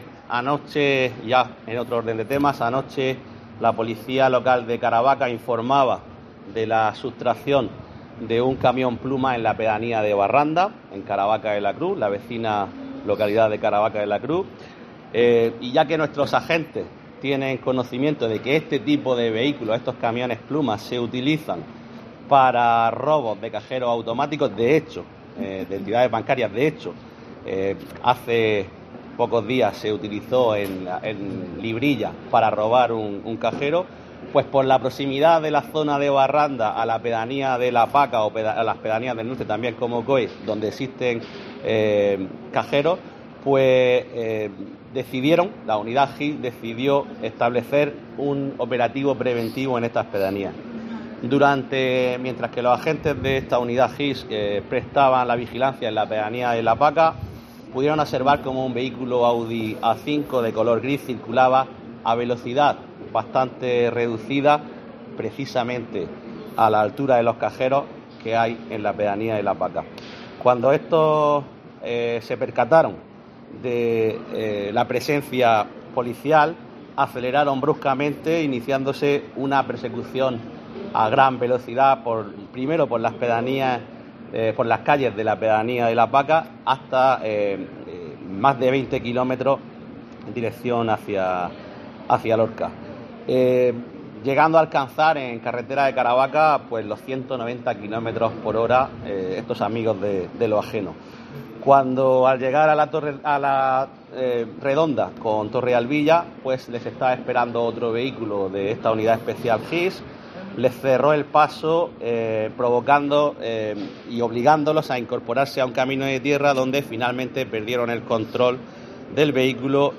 Juan Miguel Bayonas, edil Seguridad Ciudadana de Ayuntamiento de Lorca